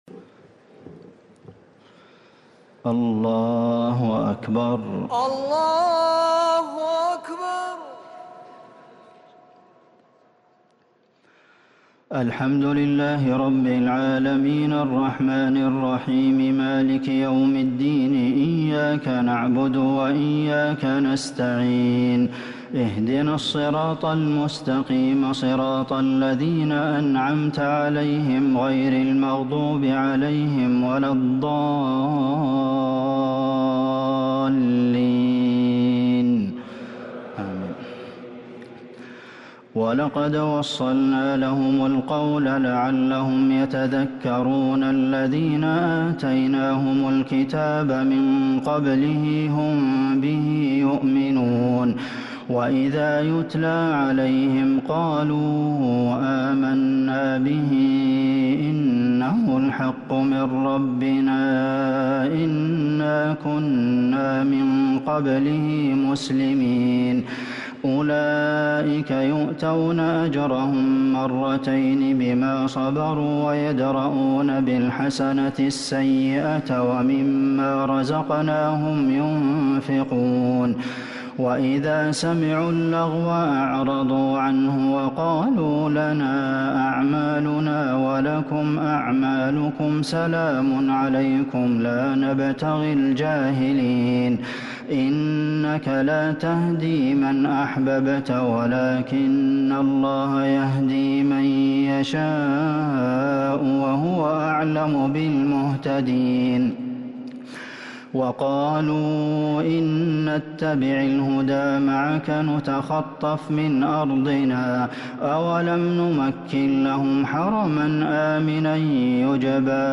تهجد ليلة 23 رمضان 1444هـ من سورتي القصص (51-88) و العنكبوت (1-35) Tahajjud 23st night Ramadan 1444H Surah Al-Qasas and Al-Ankaboot > تراويح الحرم النبوي عام 1444 🕌 > التراويح - تلاوات الحرمين